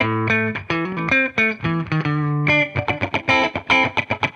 Index of /musicradar/sampled-funk-soul-samples/110bpm/Guitar
SSF_TeleGuitarProc2_110A.wav